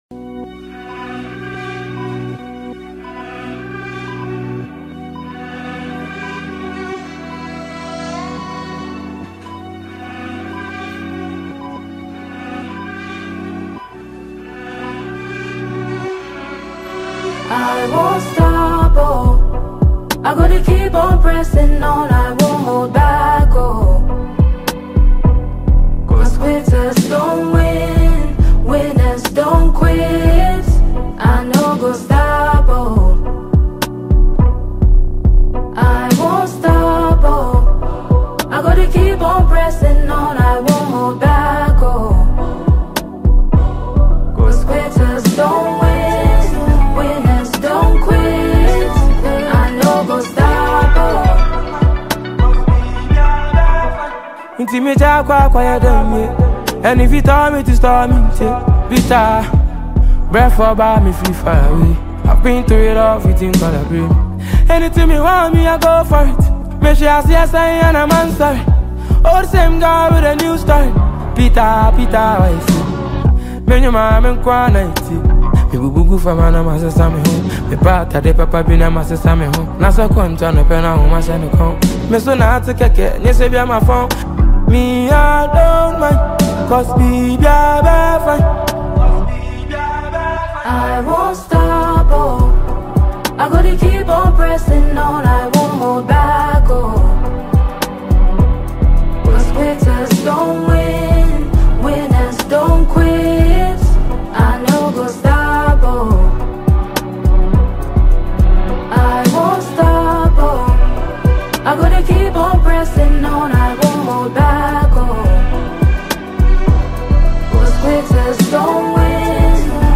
a Ghanaian singer